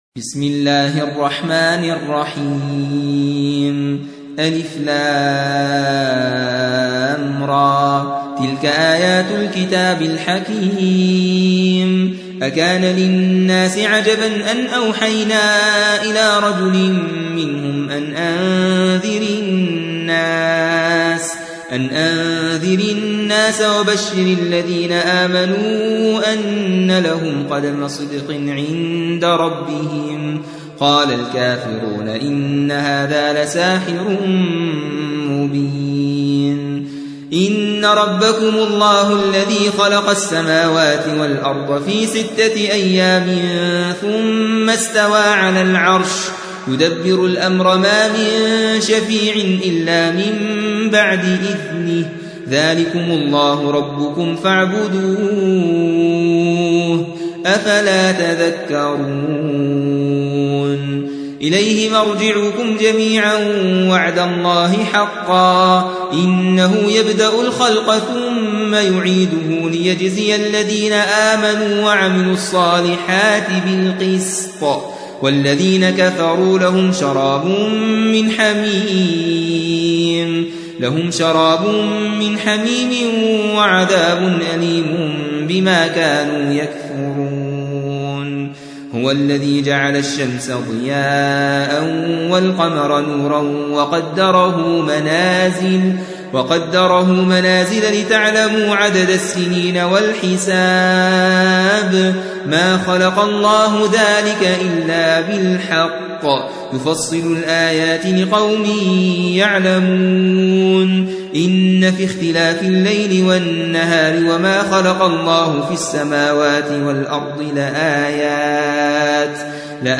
10. سورة يونس / القارئ